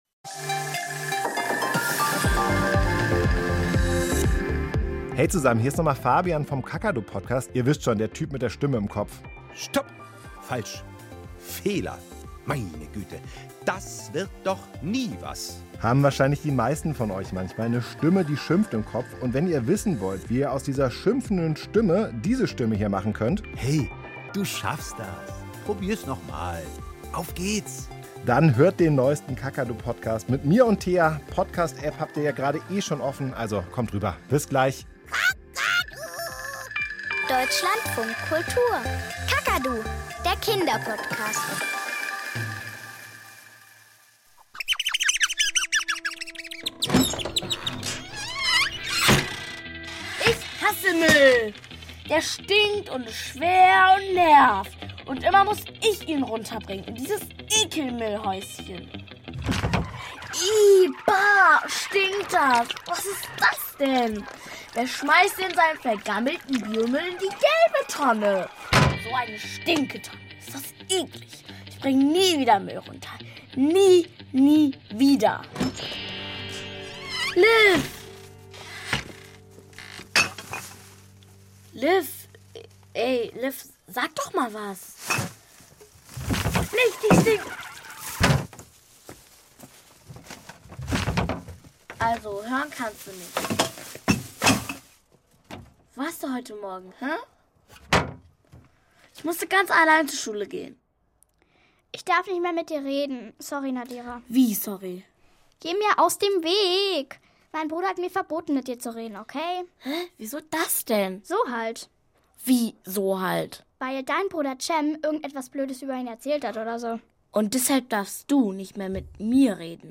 Im Kinderhörspiel von Deutschlandfunk Kultur werden Geschichten lebendig. In unseren Hörspielstudios zaubern wir die unterschiedlichsten Welten herbei: einen Elfenwald, die Tiefsee oder eine Mäusehöhle. Und all das nur durch Musik, Geräusche und die Spielfreude der Schauspieler und der vielen begabten Kinder.